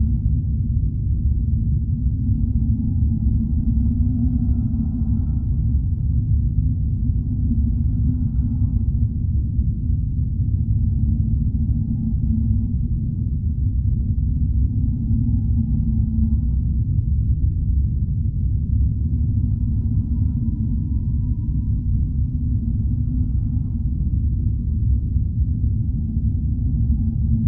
mining tunnel.ogg